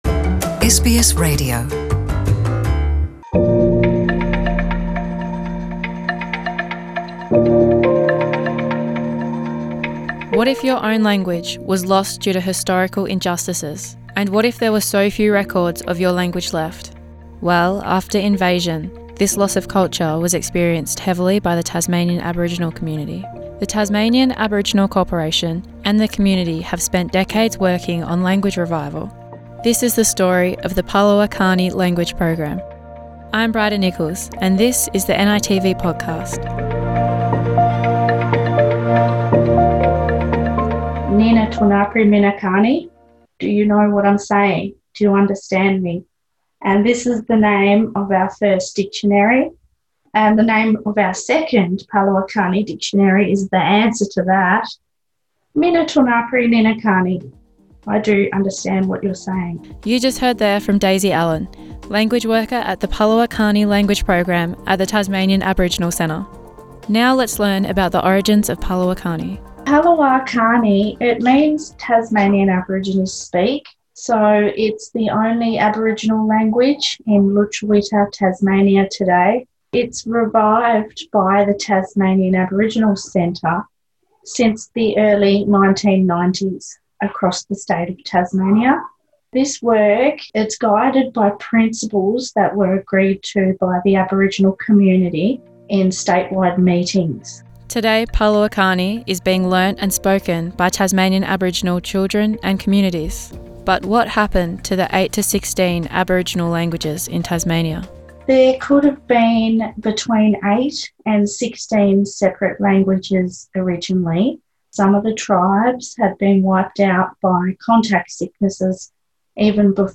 In this first episode of the palawa kani language podcast special we explore the history, construction of the language, how it is taught today and the future of palawa kani language. Interview